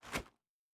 Jump Step Gravel B.wav